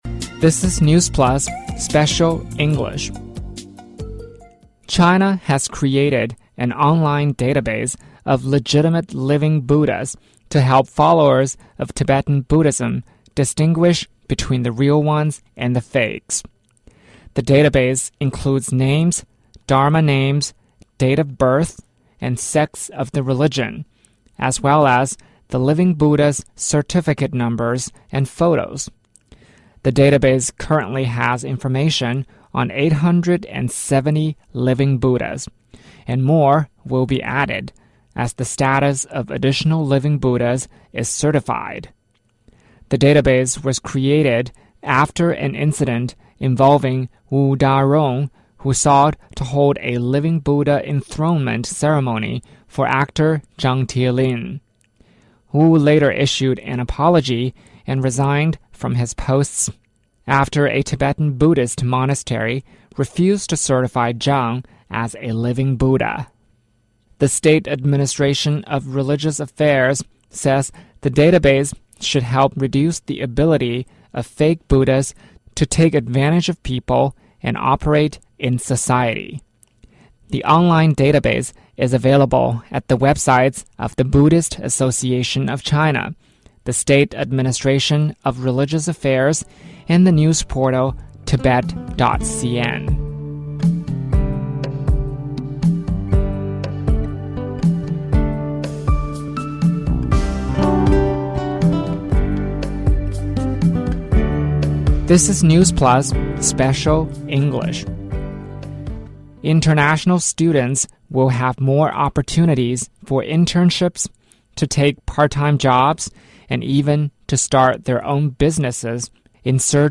News
News Plus慢速英语:活佛查询系统上线 在京外国留学生将能兼职创业